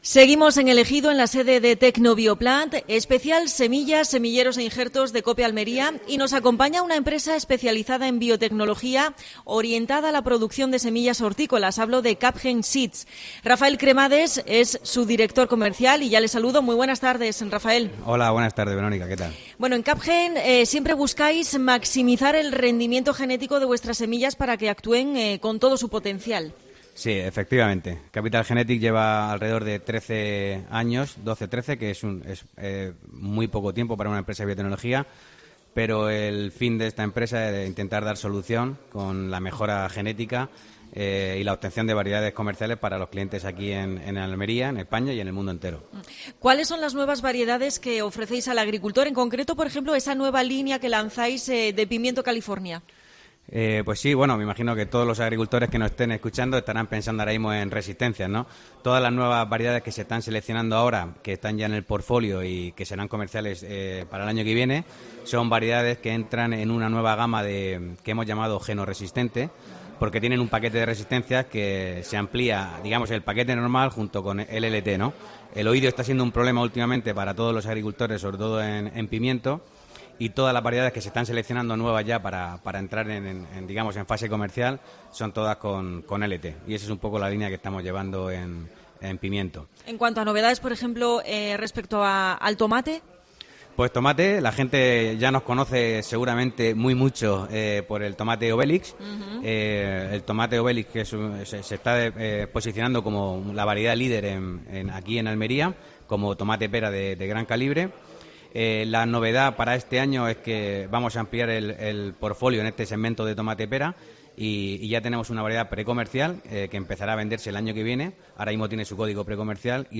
Especial semillas: entrevista